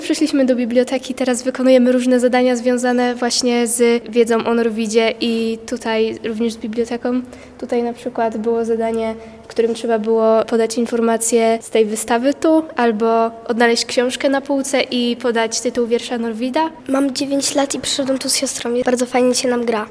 Uczestnicy z dużym zainteresowaniem rozwiązują zadania: